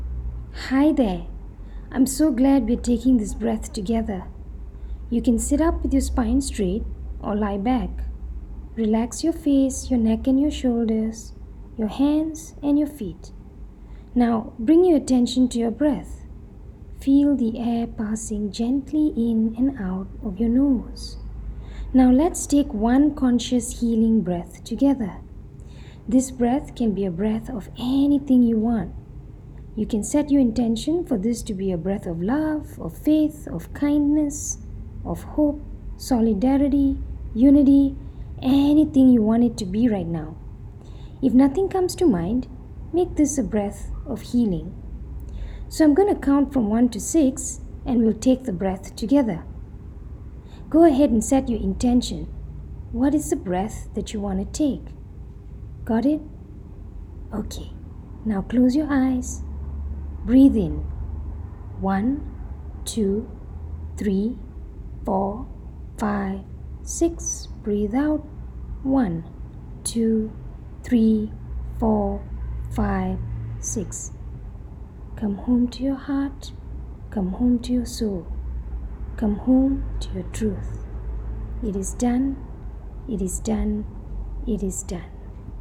And if you’d like a little guidance and support, I’m sharing a guided audio with you today.
Just-Breathe-4-Audio-Guided-Moment.m4a